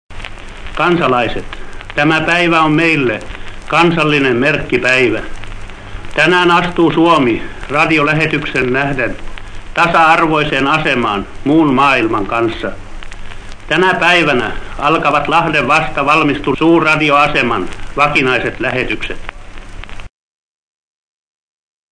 In seiner Rede bedankt sich Präsident Kallio für das Hilfsangebot.